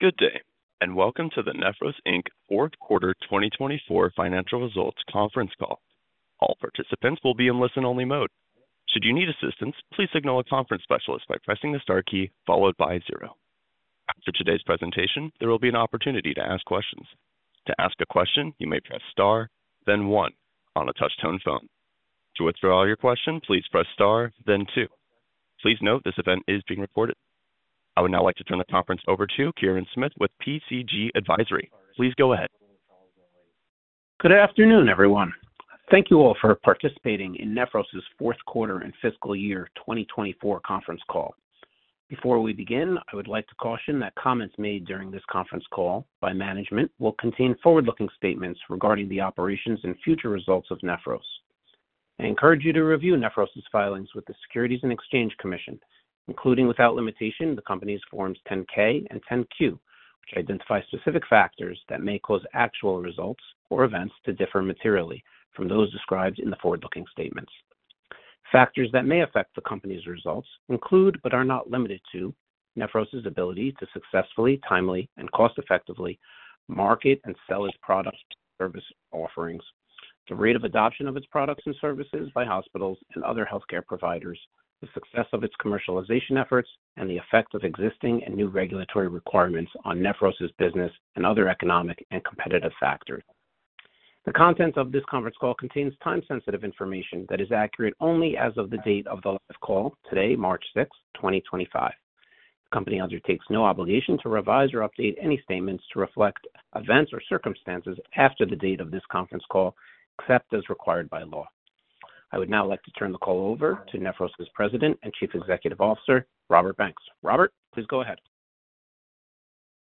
Listen to the replay of the Q4 / FY 2024 conference call